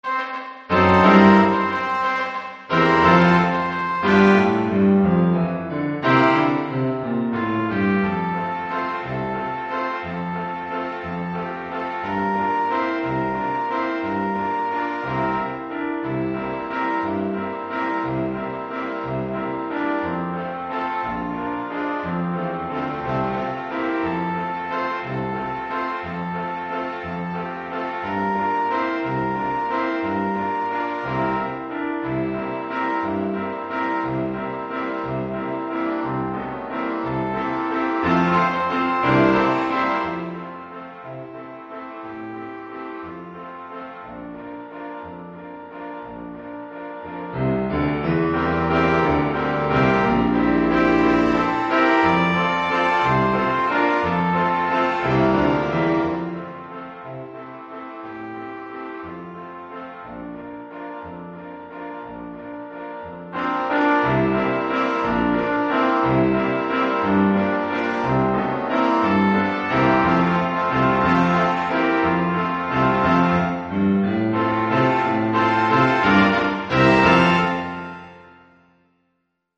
Gattung: 5-Part Ensemble
Besetzung: Ensemble gemischt
Keyboard, Drums & Percussions optional.